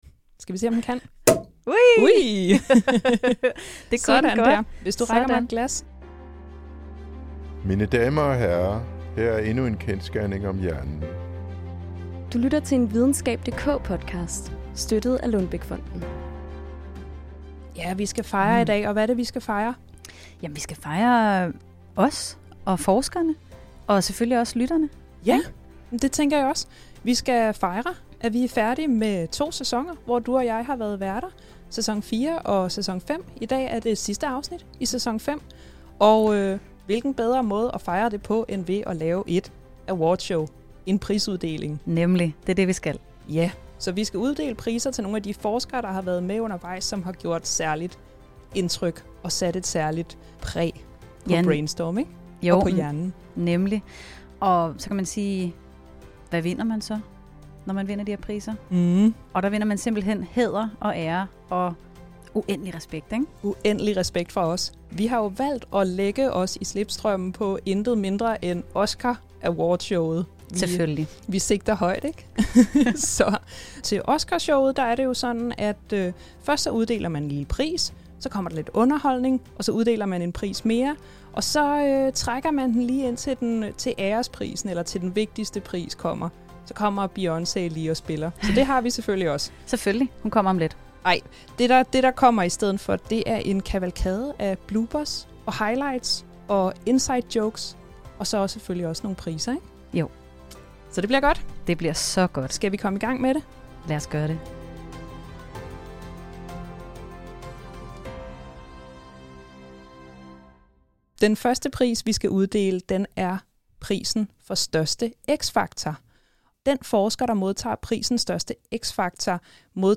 Og det markerer værterne med et awardshow, hvor de serverer en kavalkade af højdepunkter og fraklip - og uddeler priser, naturligvis, til nogle af de forskere, der har gjort et særligt indtryk i den forgangne sæson.